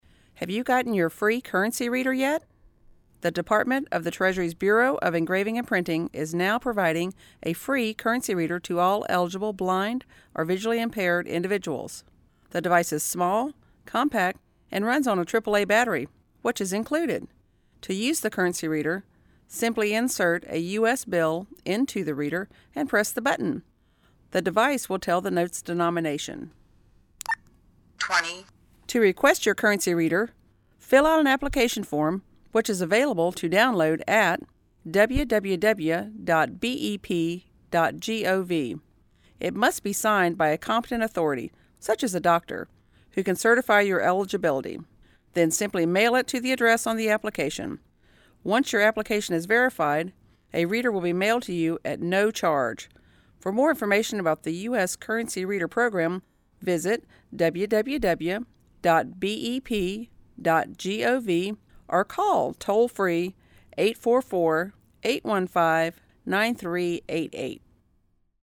:60 iBill Public Service Announcement